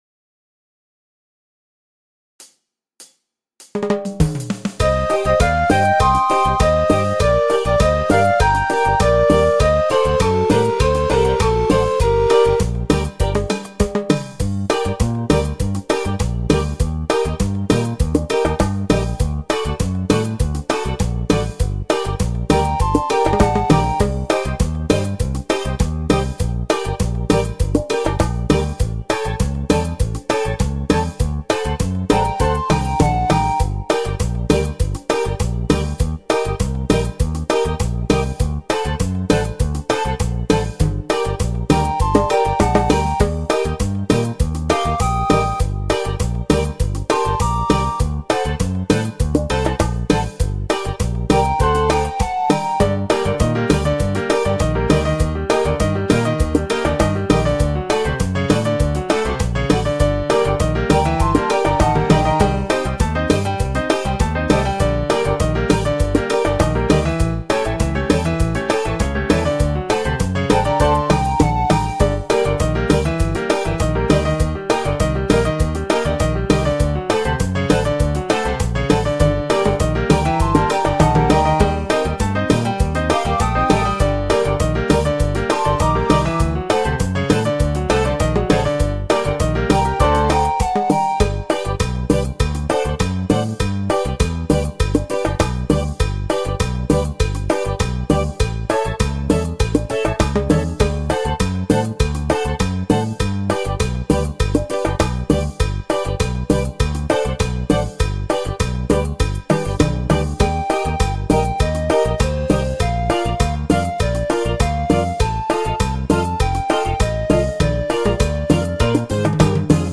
mp3 karaoke delle nostre canzoni